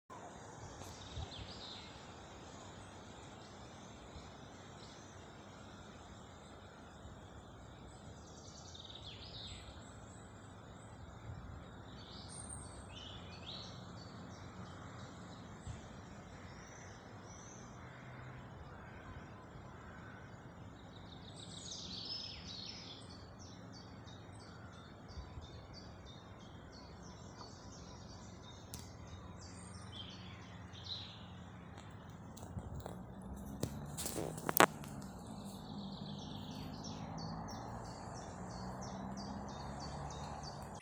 Čuņčiņš, Phylloscopus collybita
Ziņotāja saglabāts vietas nosaukumsRojas kapi
StatussDzied ligzdošanai piemērotā biotopā (D)